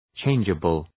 Shkrimi fonetik {‘tʃeındʒəbəl}